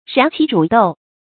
燃萁煮豆 注音： ㄖㄢˊ ㄑㄧˊ ㄓㄨˇ ㄉㄡˋ 讀音讀法： 意思解釋： 比喻骨肉相殘。